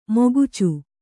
♪ mogucu